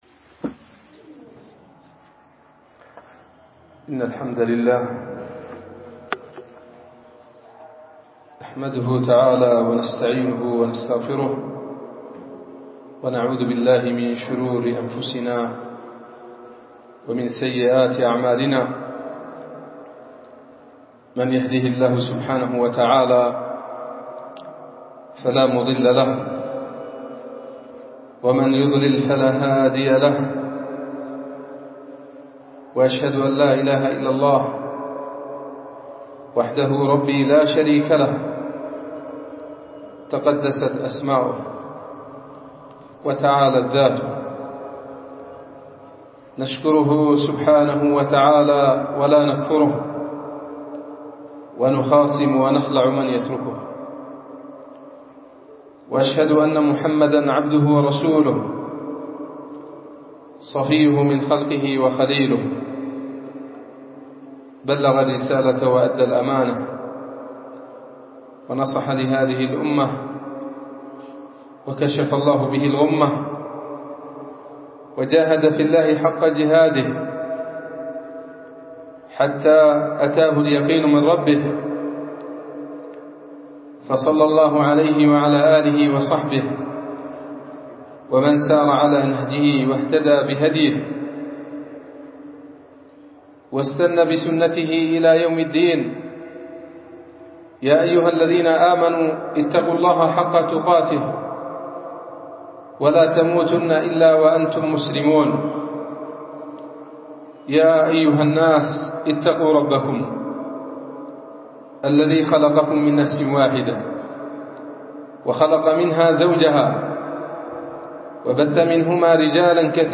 خطبة الاستسقاء
سجلت هذه المـادة بمجمع قرية منزل جوزة مديرية ريف إب محافظة إب